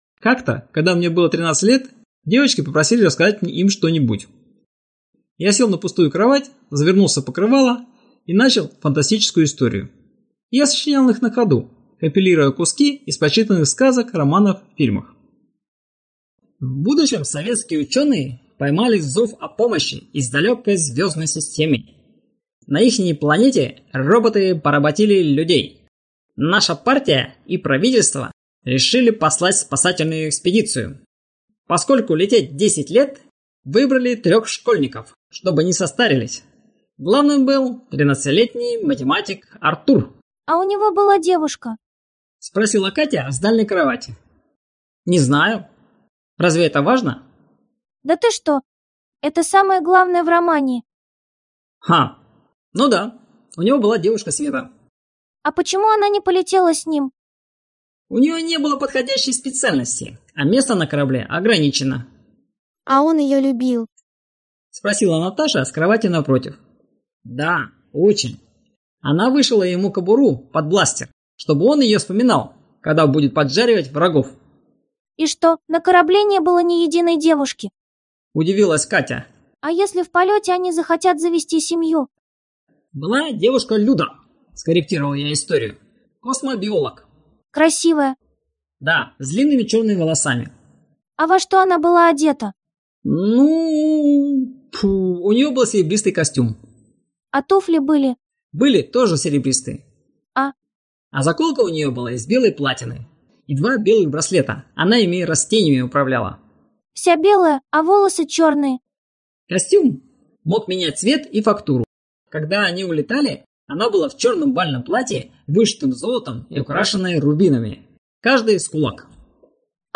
Короткие веселые автобиографические истории о советском детстве мальчишки из небольшого городка 70-х гг XX века. Школа, дом, двор, друзья и подруги, увлечения, все с деталями того времени легким разговорным языком. 305 историй, 18 часов озвучки. 95% – голос автора 4.5% – озвучка нейросетями 0.5% – голоса из СССР Перед озвучкой книга была существенно переработана и дополнена.